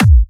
VEC3 Bassdrums Trance 55.wav